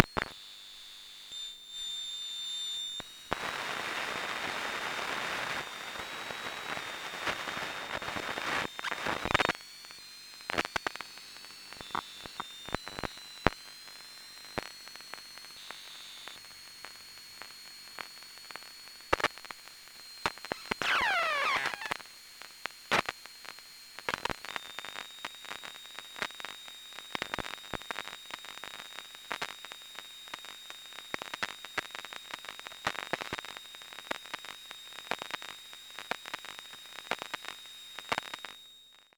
Index of /media/EM_Pickup
Virgin Media cable modem - EM.flac